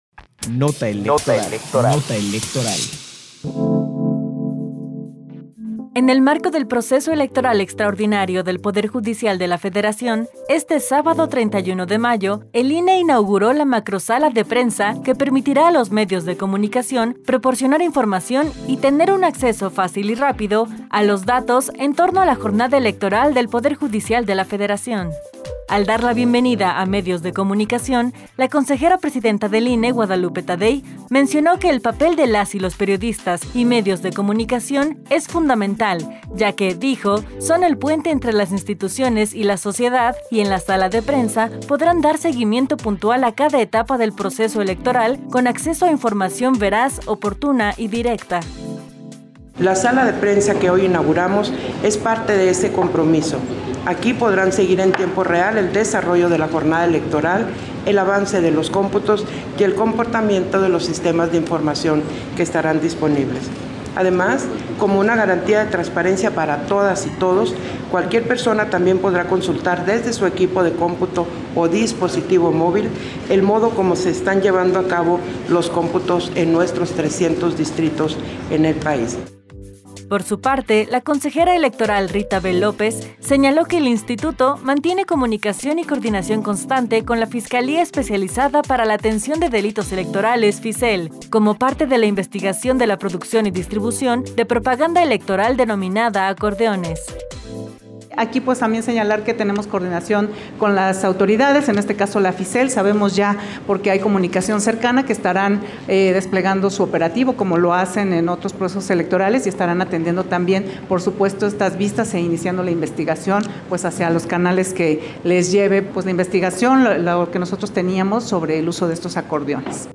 Nota de audio sobre la inauguración de la Sala de Prensa para la Jornada Electoral del PJF, 31 de mayo de 2025